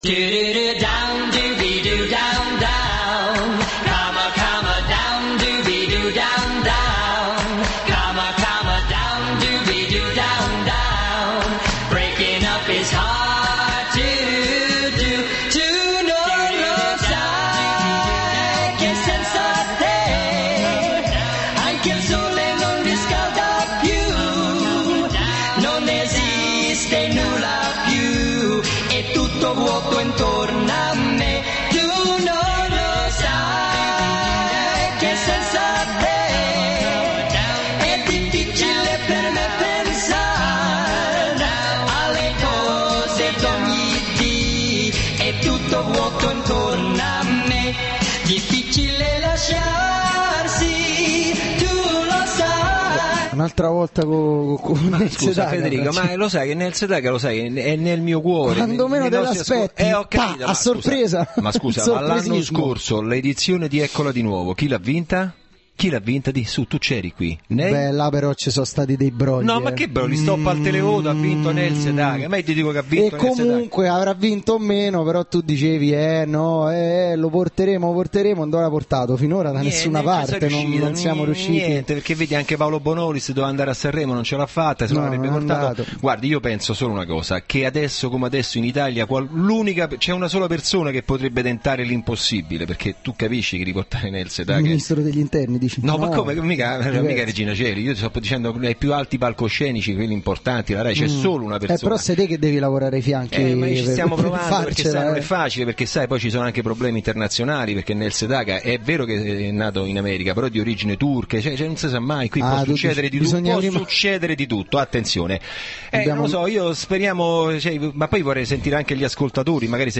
Intervento telefonico Carlo Conti